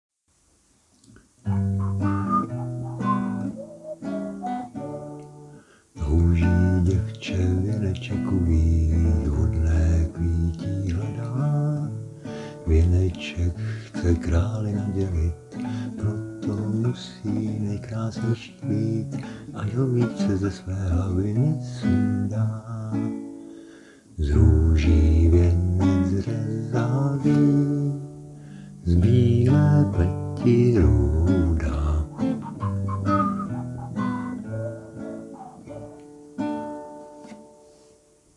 :-) Až to dopíšu a upravím, tak se pokusím o lepší nahrávku.